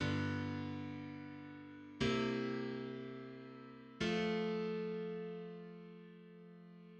Secondary dominant with barbershop seventh chords. V/V - V - I in F major (G7-C7-F).
In just intonation. Sevenths are harmonic sevenths, and the F in the first measure is 27.26 cents lower than the F in the third measure.
Barbershop_secondary_dominant.mid.mp3